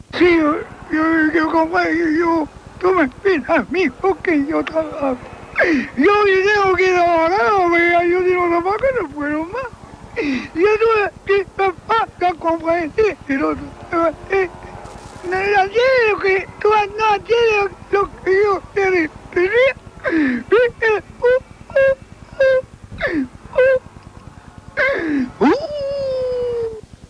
Actuació del pallasso Charlie Rivel (Josep Andreu).
Extret de Crònica Sentimental de Ràdio Barcelona emesa el dia 22 d'octubre de 1994.